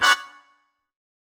GS_MuteHorn-Bmin9.wav